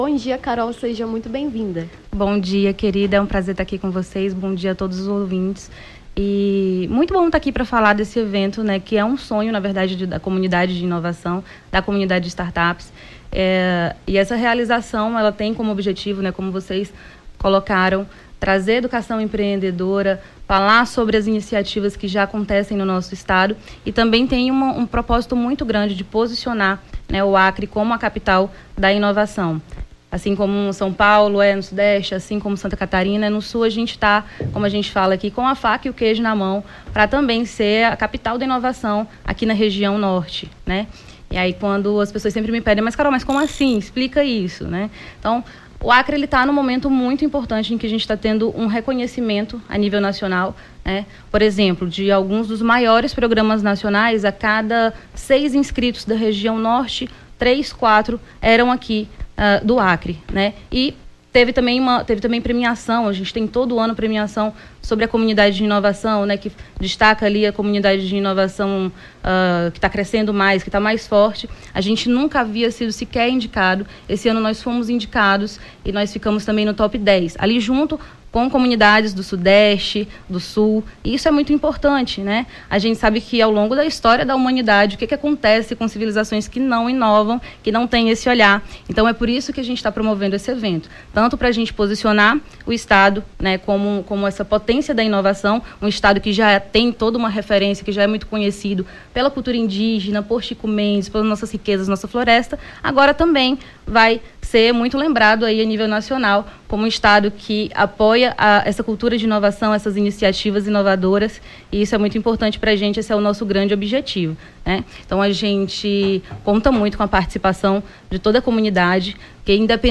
Nome do Artista - CENSURA - ENTREVISTA (INOVAQUIRI) 09-11-23.mp3